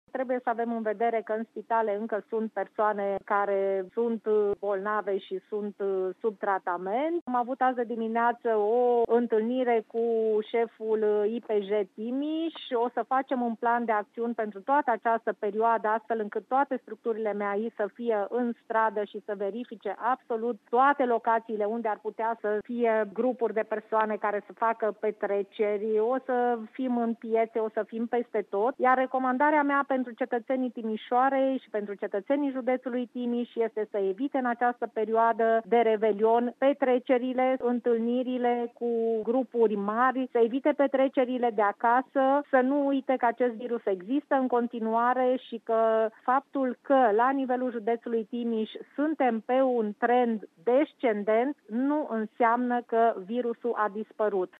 Pe de altă parte, polițiștii își vor intensifica verificările în perioada imediat următoare, spune prefectul de Timiș, Liliana Oneț.